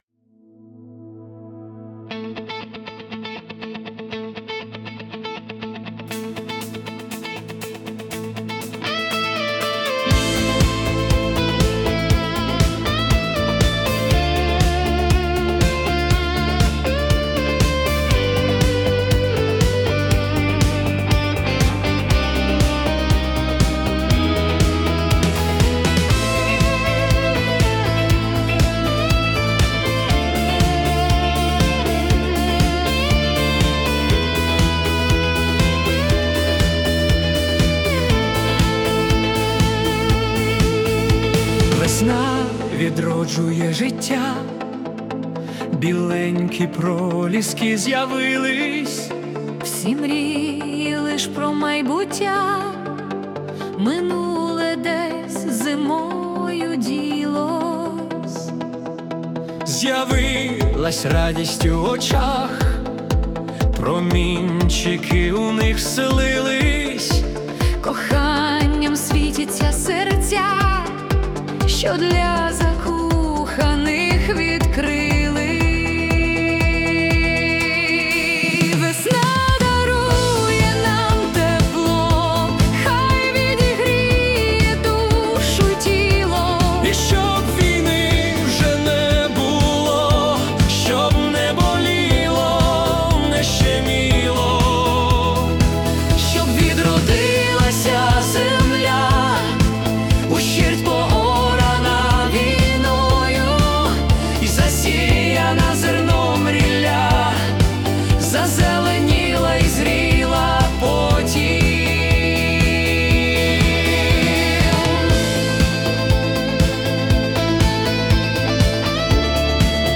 🎵 Жанр: Italo Disco / Spring Anthem